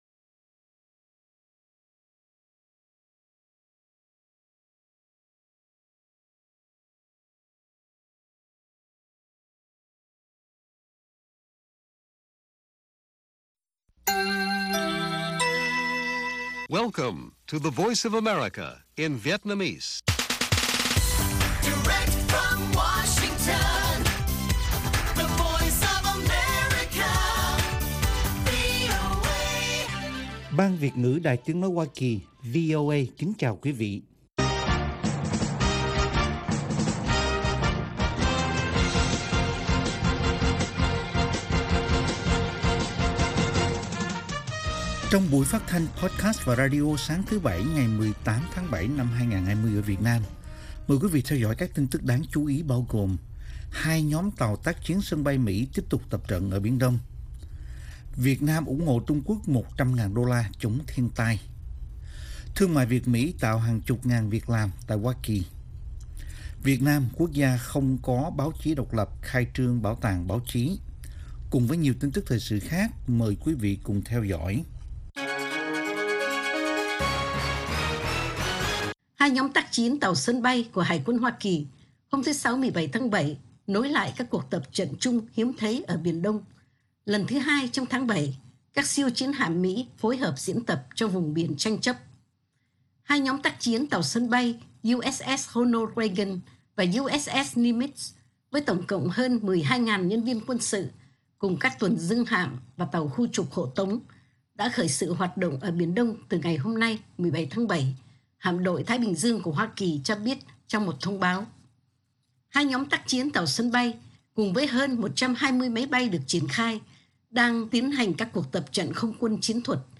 Bản tin VOA ngày 18/7/2020